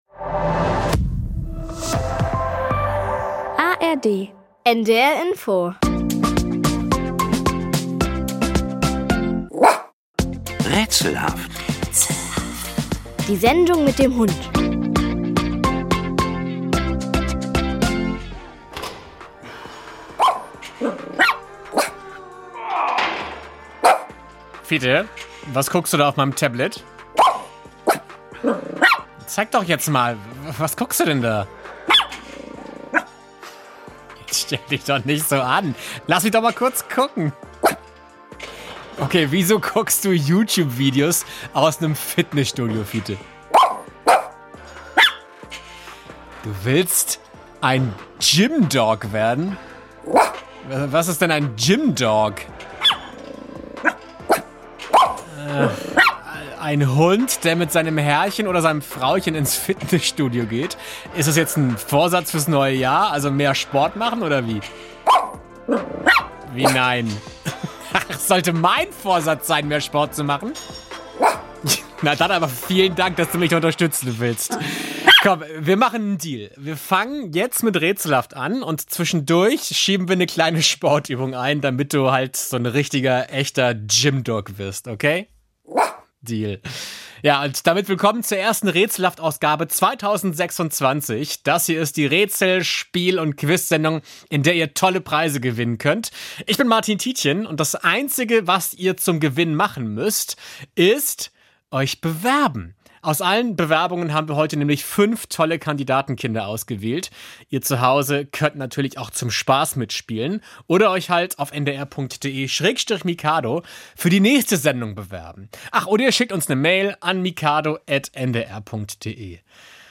In verschiedenen Raterunden treten Kinder gegeneinander an. Wer ist am besten beim Lösen der kniffligen, verdrehten, lustigen Rätsel? Schiedsrichter Fiete, ein frecher kleiner Hund, behält stets den Überblick.